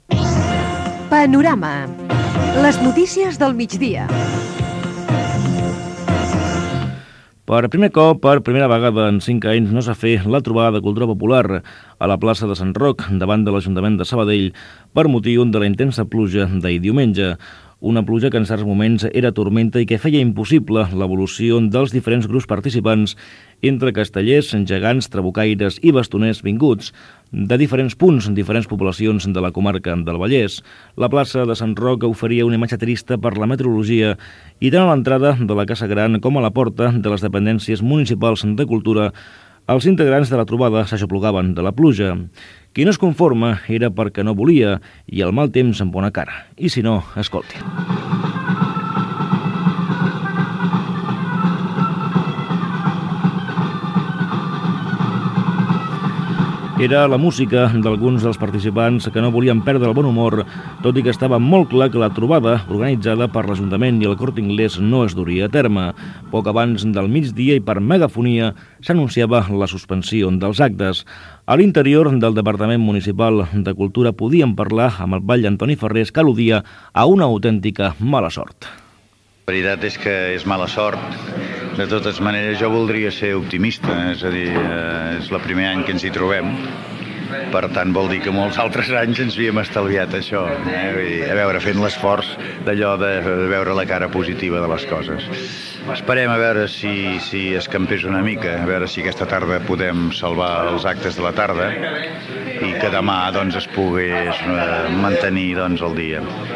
Indicatiu del programa, informació sobre els actes de l'Aplec de la Salut , afectats pel mal temps. Declaracions de l'alcalde Antoni Farrés
Informatiu